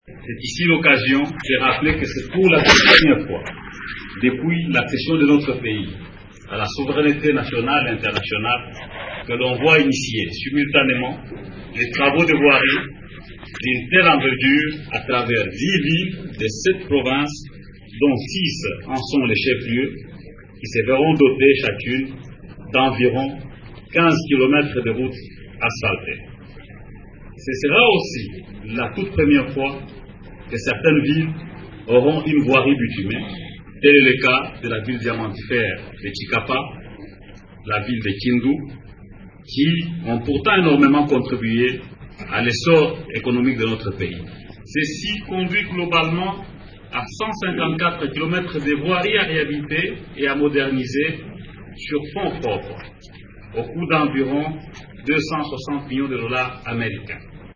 Extrait de l’allocution du ministre Fridolin Kasweshi.
Fridolin-Kasweshi-154-km-des-routes-dans-7-provinces.mp3